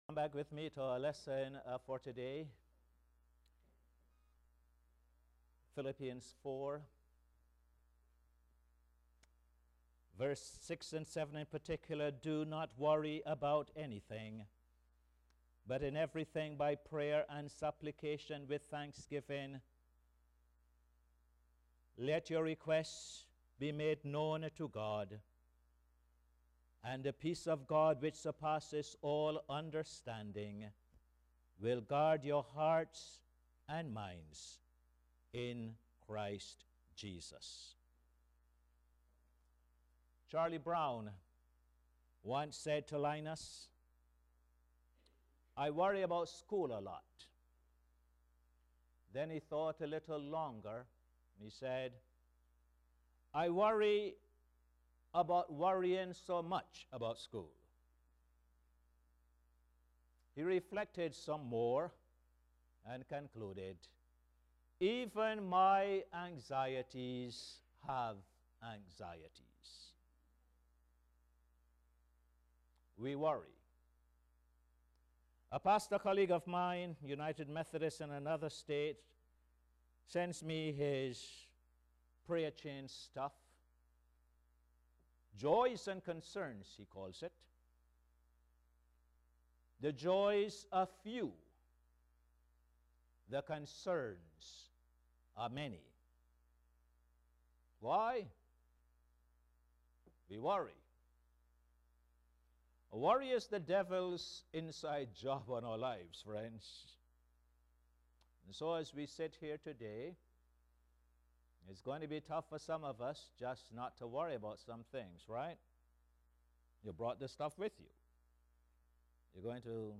Posted in Sermons on 30. Jan, 2012